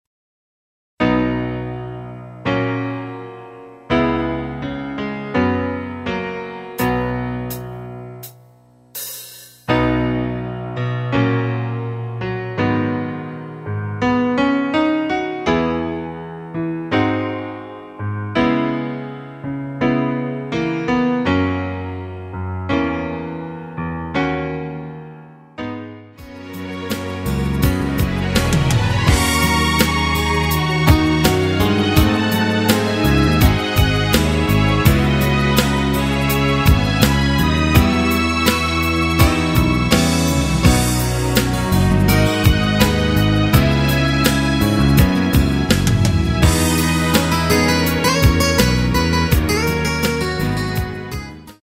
전주없이 노래 들어가는 곡이라 전주 만들어 놓았습니다.